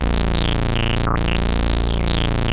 Index of /90_sSampleCDs/Hollywood Edge - Giorgio Moroder Rare Synthesizer Collection/Partition A/ARP 2600 7